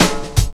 MPC3 FILL2.wav